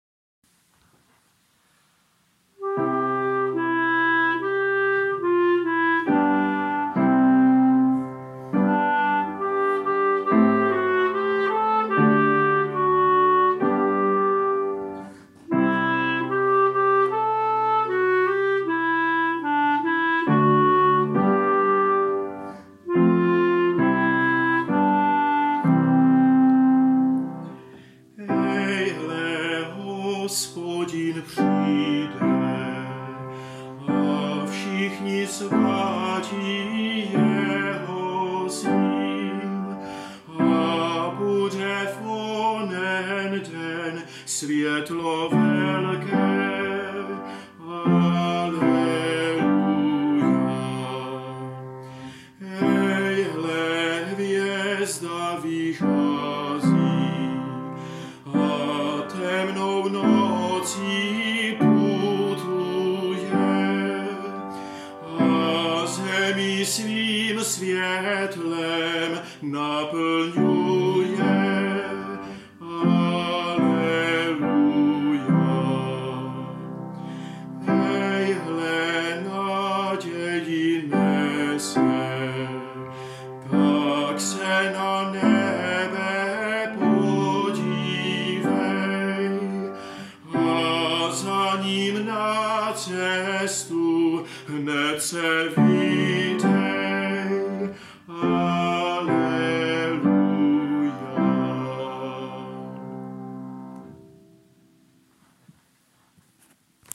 Dnes se zaměřil na měsíc a při tom si zpívá o příchodu Krále.